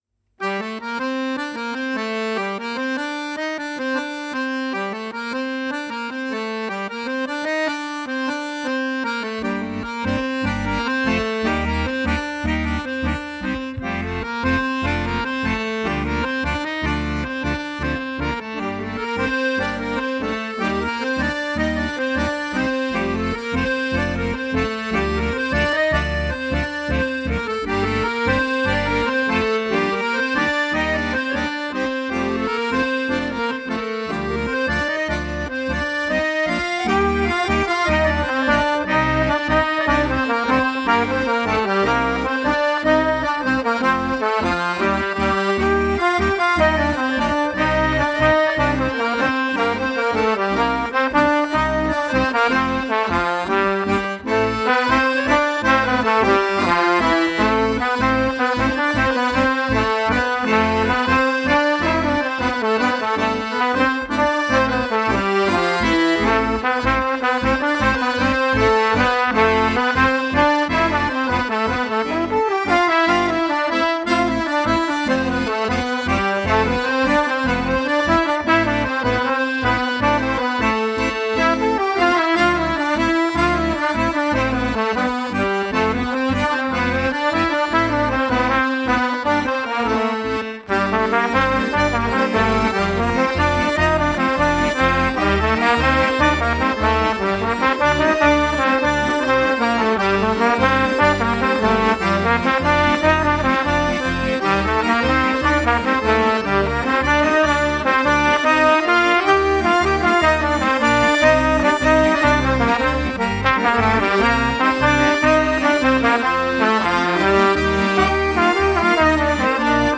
Muzycy grający na akordeonie i trąbce.
trumpet
accordion
crank lyre
vocals